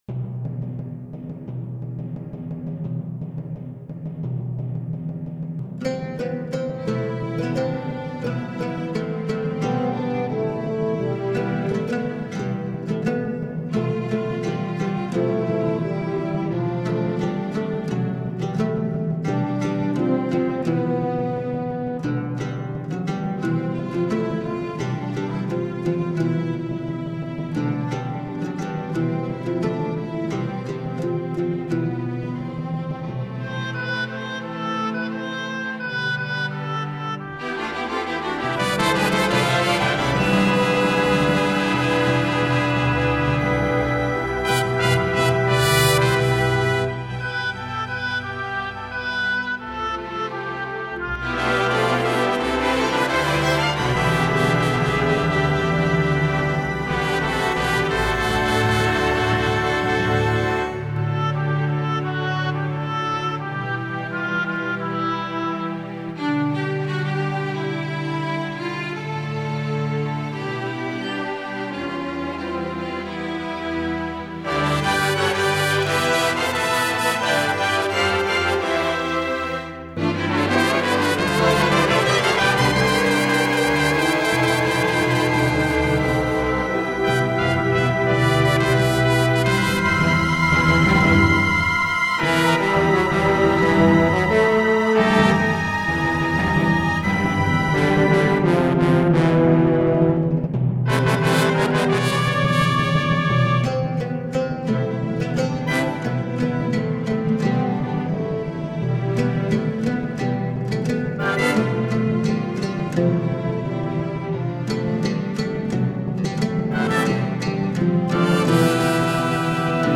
برای ارکستر سمفونیک و ساز عود